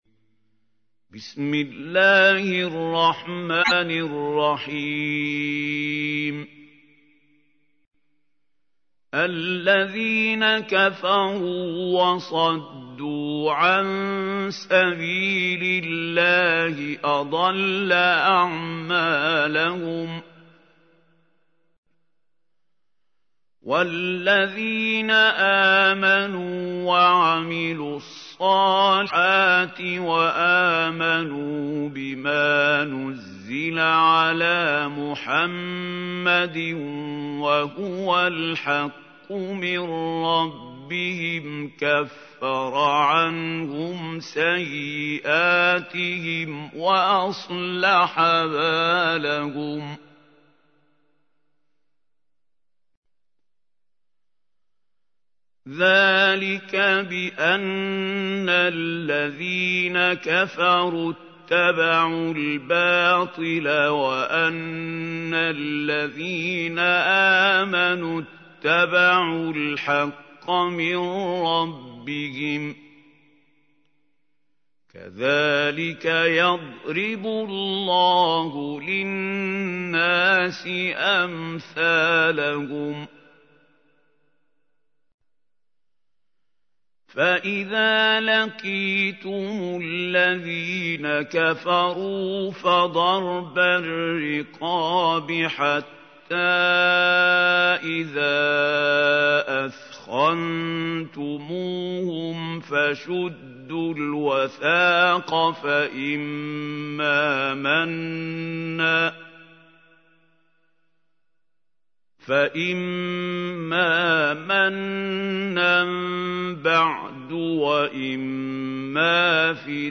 تحميل : 47. سورة محمد / القارئ محمود خليل الحصري / القرآن الكريم / موقع يا حسين